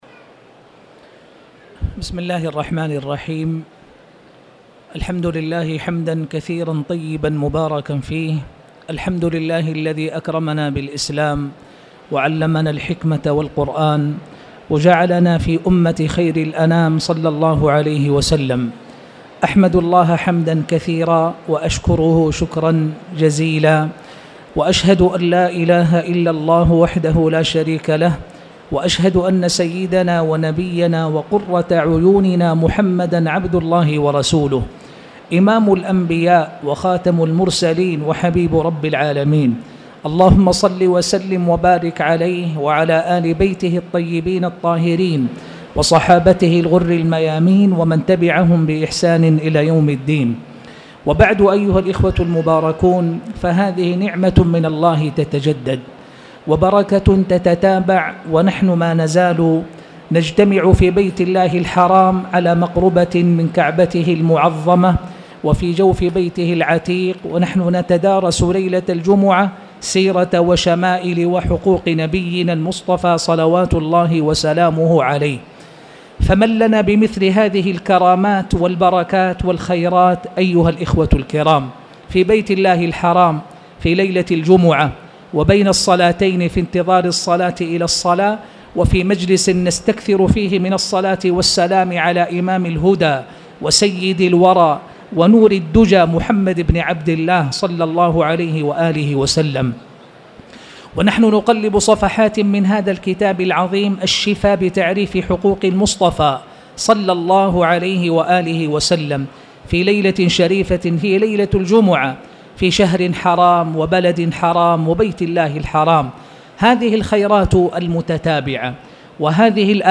تاريخ النشر ١٥ ذو القعدة ١٤٣٧ هـ المكان: المسجد الحرام الشيخ